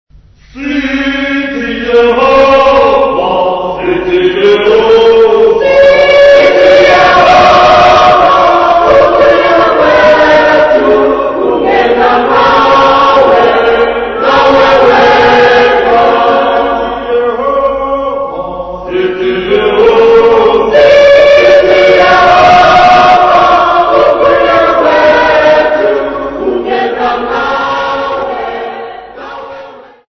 Participants at Garankuwa Workshop
Folk music
Field recordings
Participants at Garankuwa workshop perform own composition with drum, clapping and stamping accompaniment.